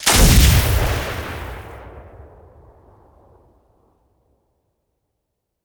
sniper2.ogg